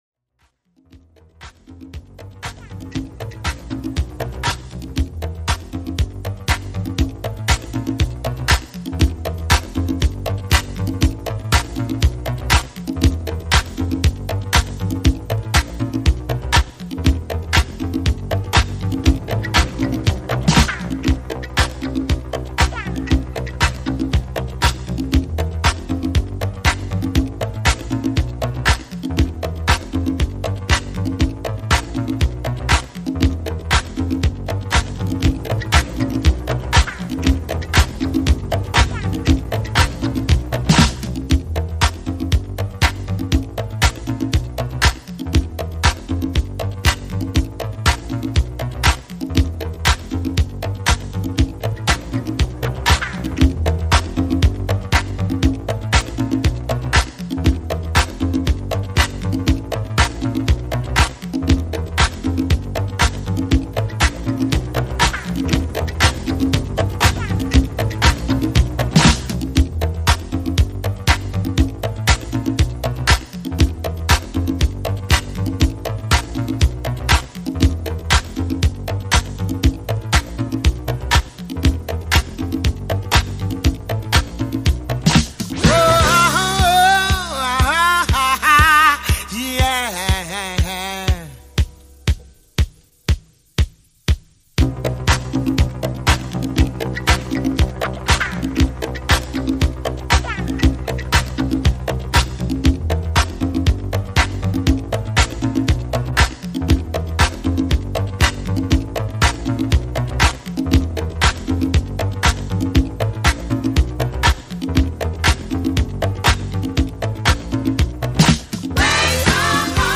ジャンル(スタイル) DISCO